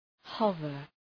Προφορά
{‘hʌvər}
hover.mp3